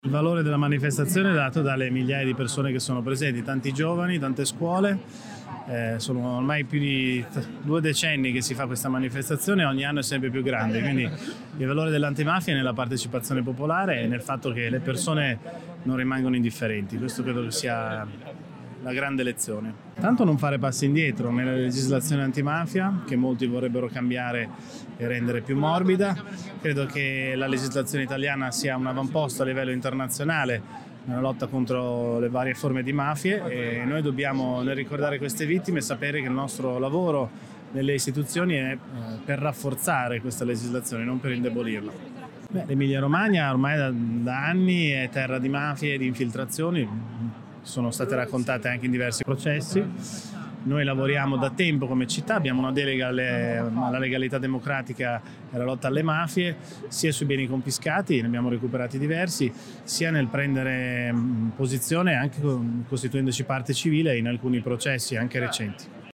Abbiamo raccolto alcune voci e riflessioni provenienti dalla società civile, dalle associazioni, dalla politica
L’intervista a Matteo Lepore, sindaco di Bologna